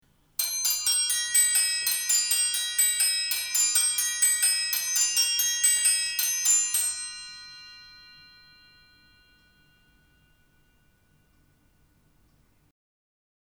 Cymbelstern mit 6 Schalenglocken, ungestimmt
6 Glocken aus Messing. Klöppel aus Messing.